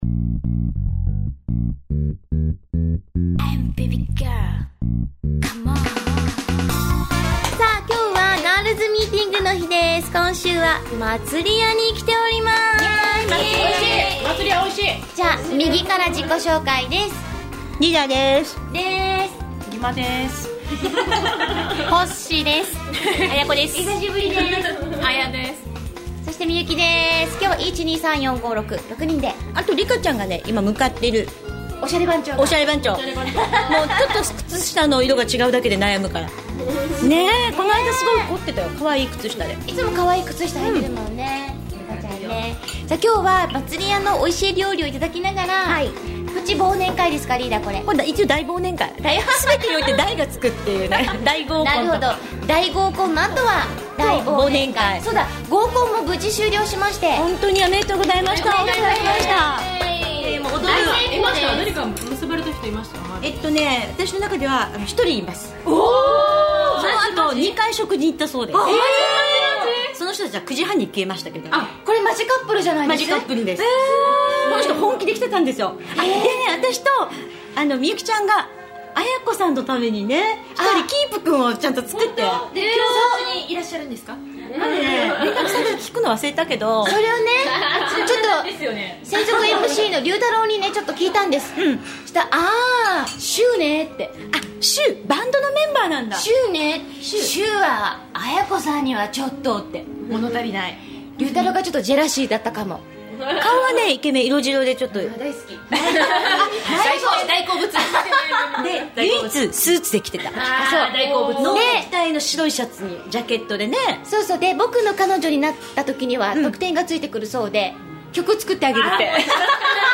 今回はGirls' Memberが７名参加しての大忘年会！！場所Girls' Memberお気に入りの麻の実料理店 「麻麻吏家(まつりや)」。